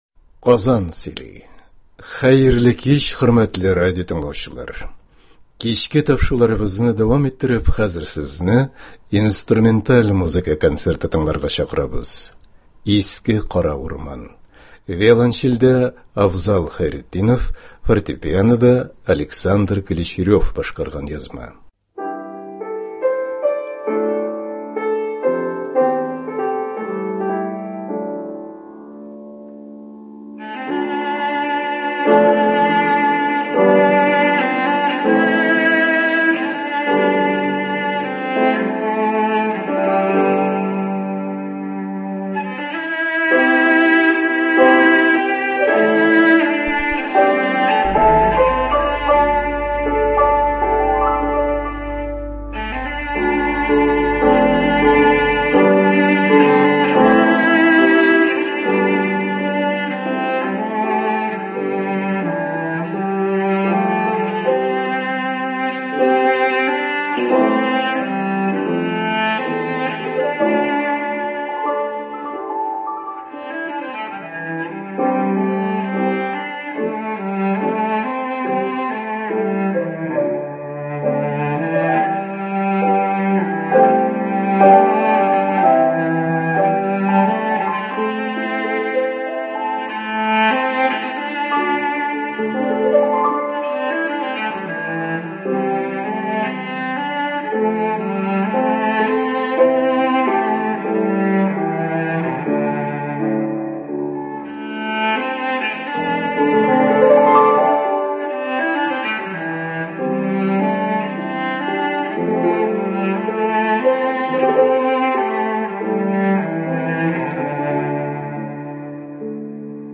Инструменталь музыка концерты.